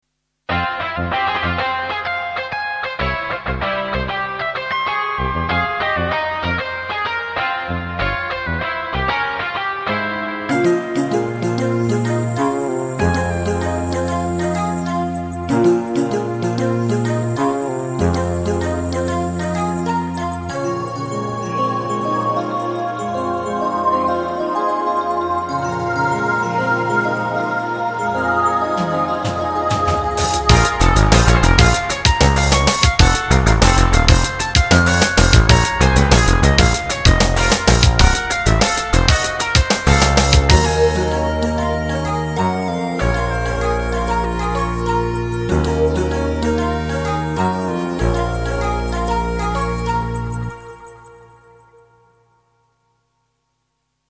Beetje elektro-symfonische punk-rock